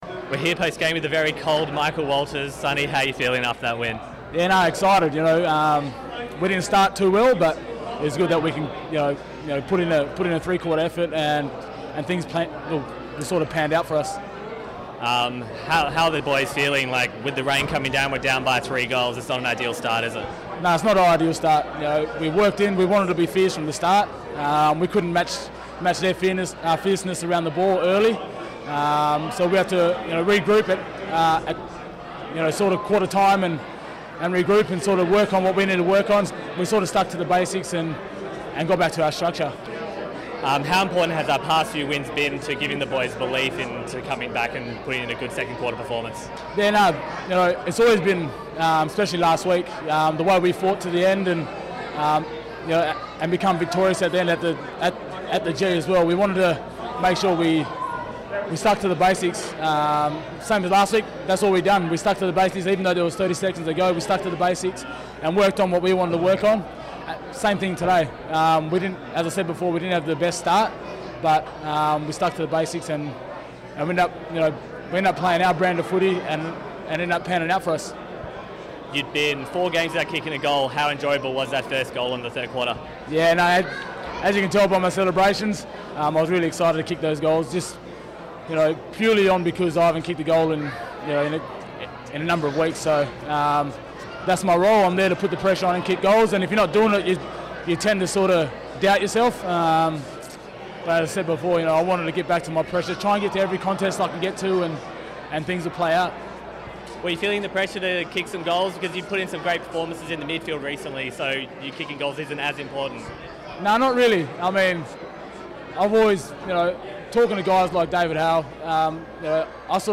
Michael Walters chats to Docker TV after Freo's win over the Blues.